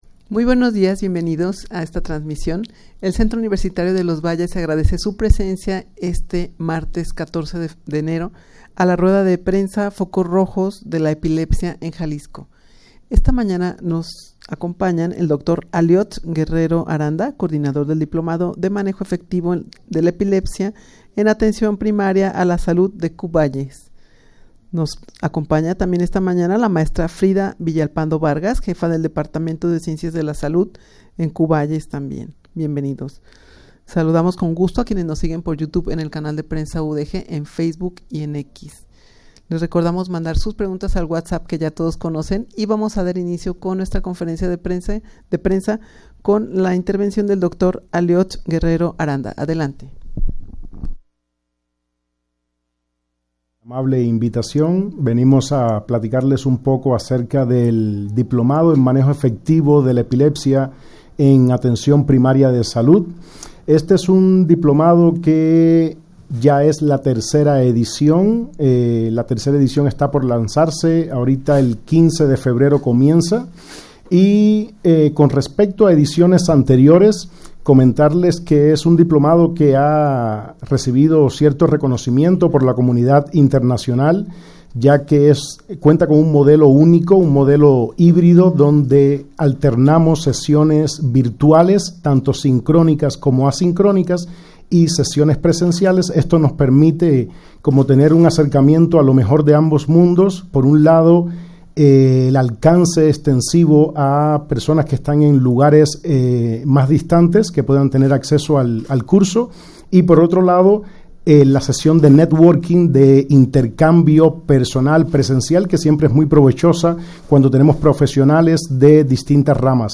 rueda-de-prensa-diplomado-de-manejo-efectivo-de-la-epilepsia-en-atencion-primaria-a-la-salud.mp3